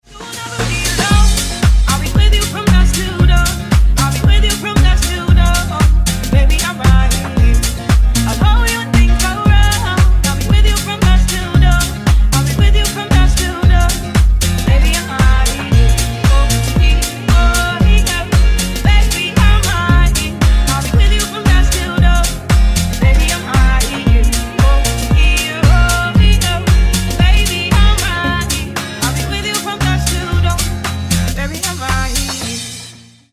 Кавер И Пародийные Рингтоны
Танцевальные Рингтоны